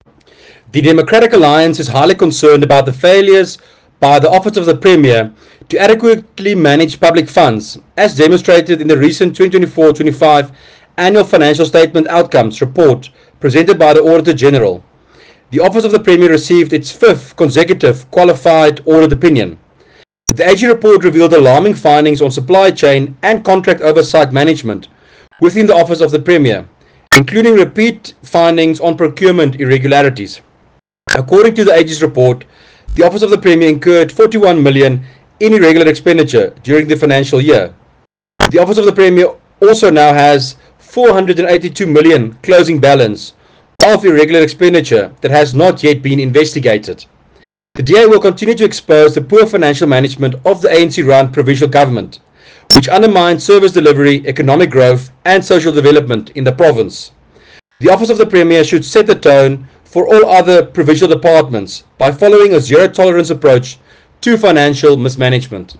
Afrikaans soundbites by Werner Pretorius MPL and Sesotho soundbite by Jafta Mokoena MPL